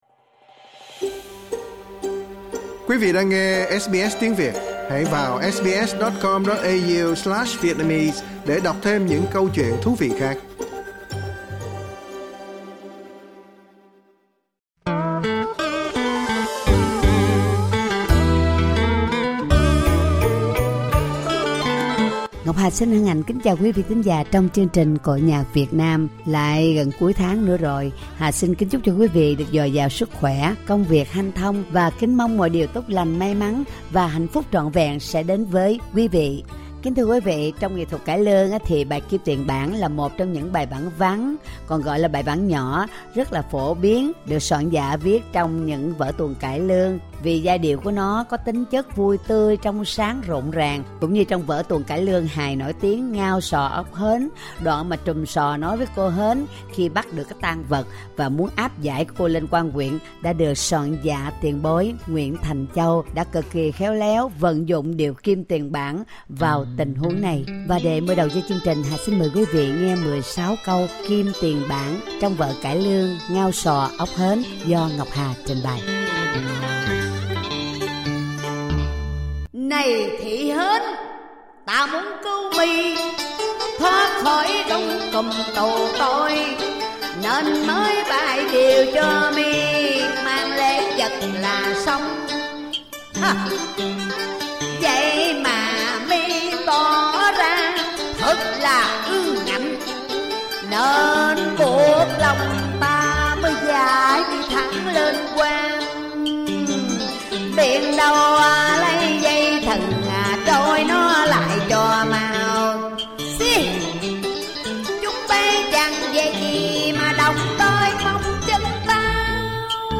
Xin mời quý vị nghe bài 'Chú Rể Không Phải Là Anh' và thể điệu Kim tiền bản, Kim tiền Huế